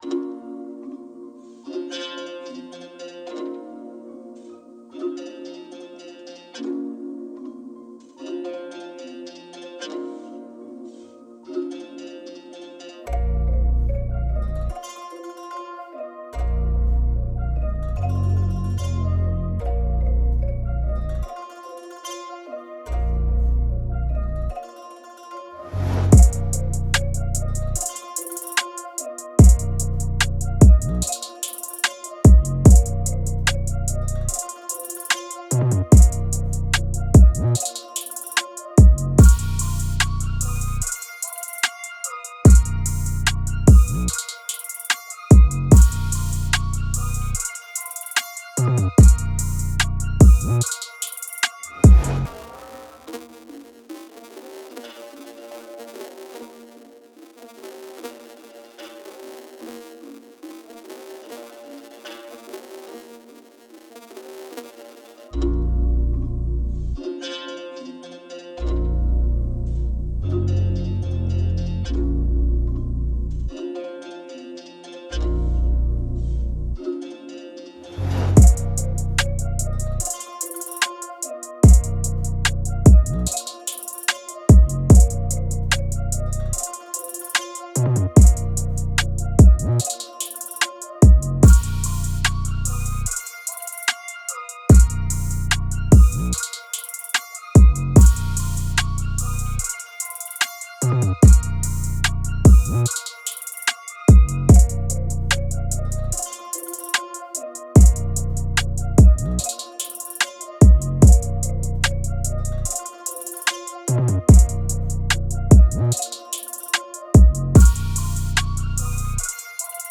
Hip Hop Instrumentals